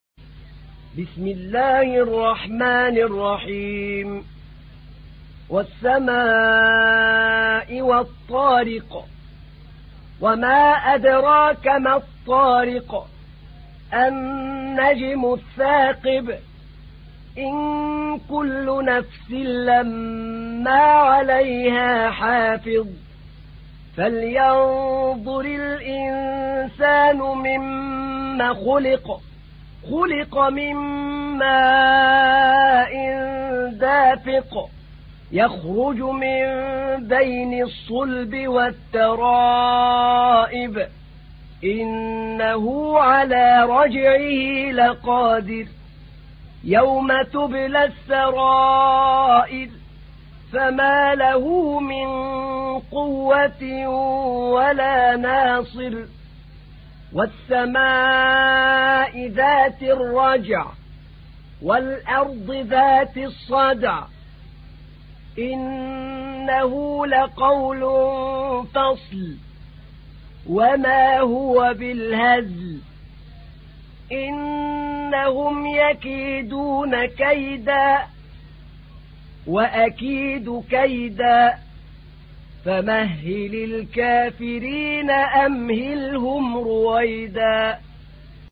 تحميل : 86. سورة الطارق / القارئ أحمد نعينع / القرآن الكريم / موقع يا حسين